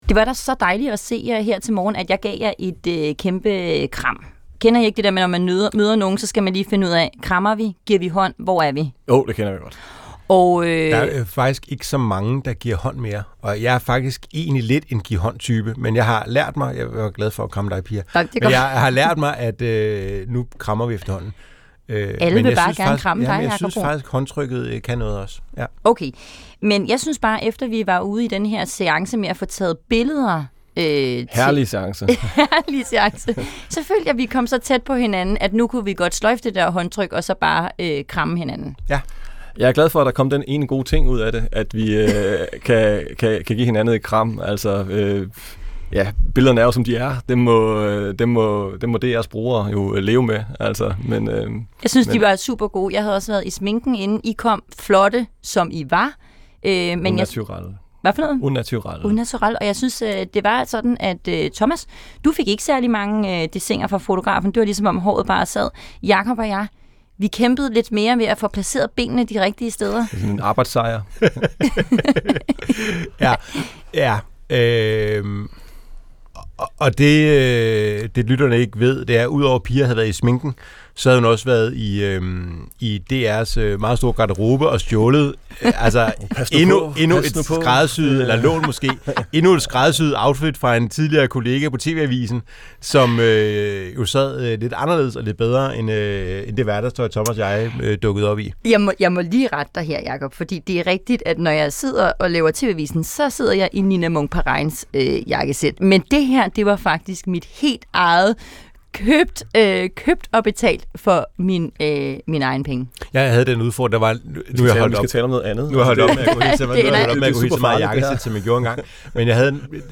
Få dansk politiks vigtigste historier. Kritiske interview med politikerne, landets bedste analytikere og DR's hold af politiske journalister i marken.